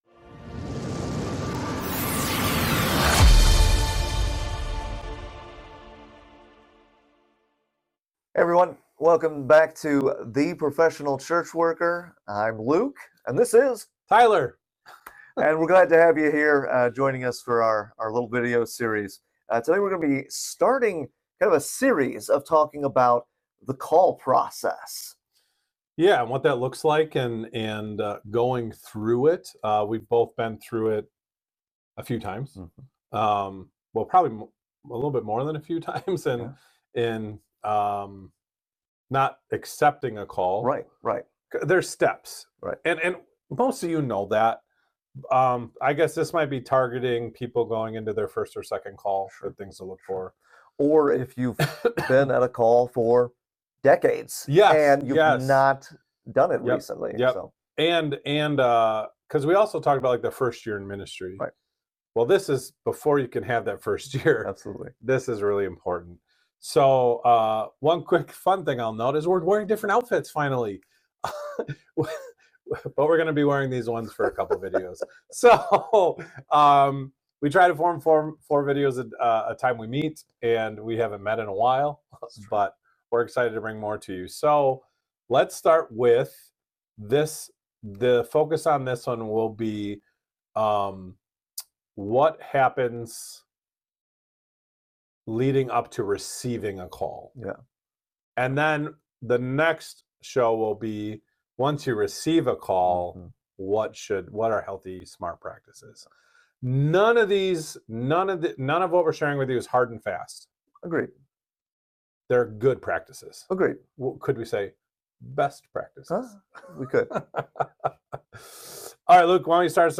Whether you’re heading into your first call, considering a second, or haven’t navigated the process in decades, this conversation offers practical wisdom, encouragement, and healthy “best practices” for what happens before a call is officially extended.